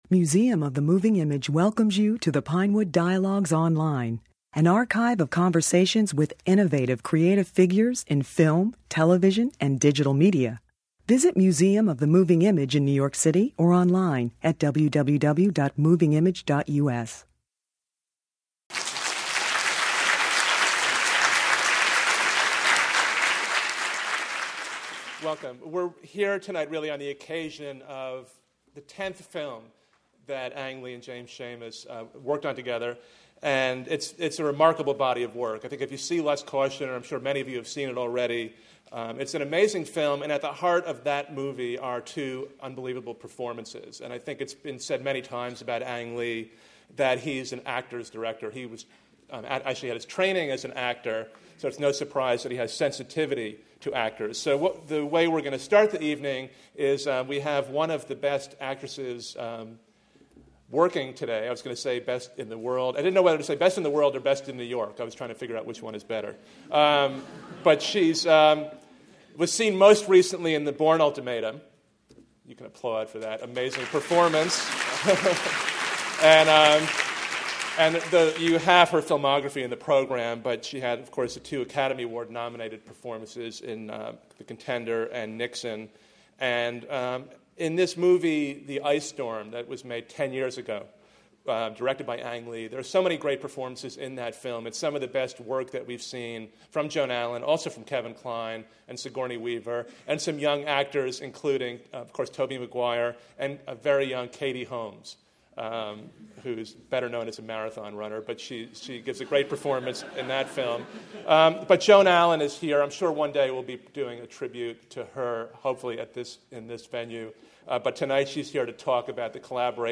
Academy Award-winning director Ang Lee and his longtime collaborator James Schamus have over the course of fifteen years made films including the current Lust, Caution (2007); Brokeback Mountain (2005); Crouching Tiger, Hidden Dragon (2000); Ride with the Devil (1999); The Ice Storm (1997); Sense and Sensibility (1995); Eat Drink Man Woman (1994); and The Wedding Banquet (1993). This evening at the Times Center included clips from their movies, a conversation, and an introduction by three-time Oscar nominee Joan Allen (The Contender (2000), The Crucible (1996), Nixon (1995)) who appeared in Le